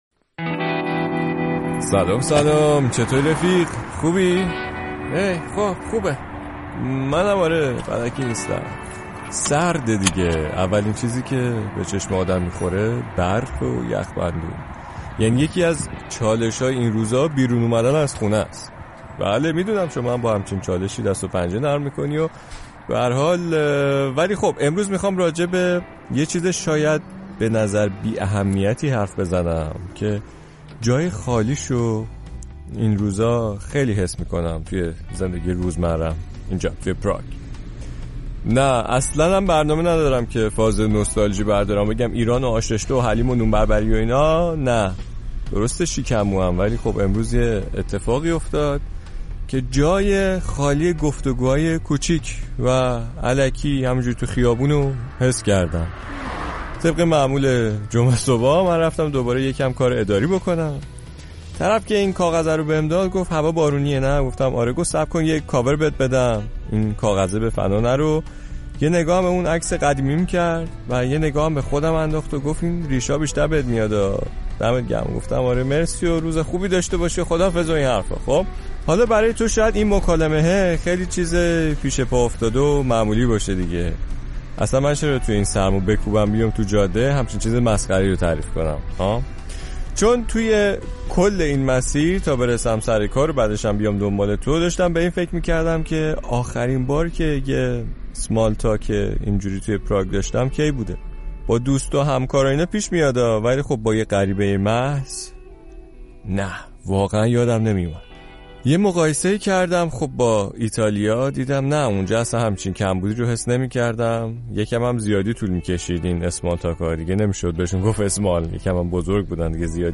پادکست موسیقی